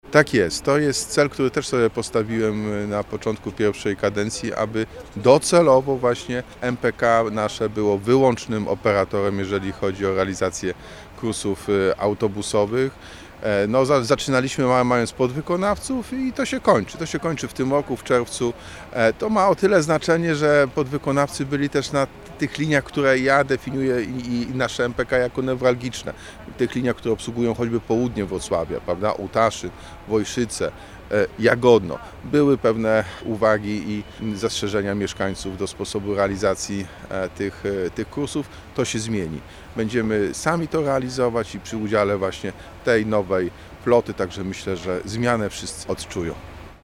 To już pewne: miasto od czerwca zrezygnuje z usług podwykonawców, a MPK Wrocław stanie się wyłącznym operatorem kursów autobusowych – podkreślił z rozmowie z Radiem Rodzina prezydent Wrocławia Jacek Sutryk.